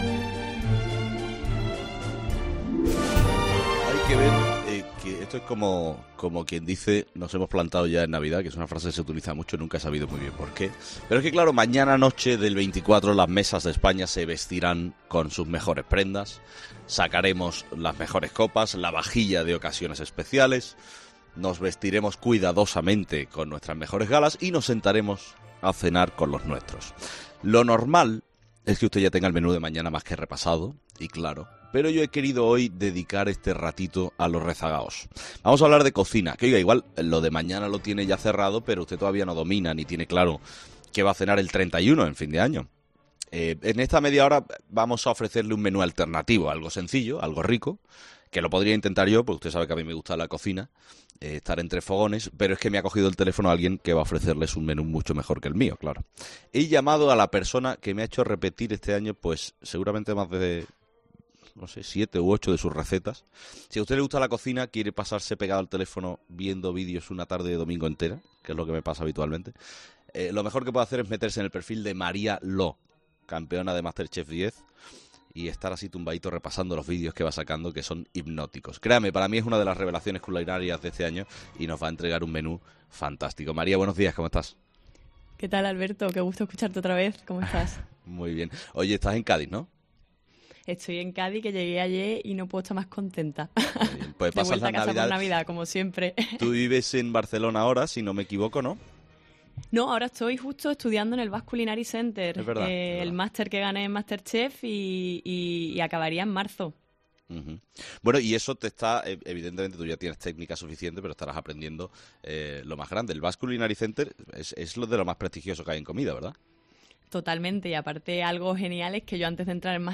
María Lo, ganadora de 'Masterchef 10', ha entrado en 'Herrera en COPE' para recomendar y explicar el menú que tiene en mente para estas navidades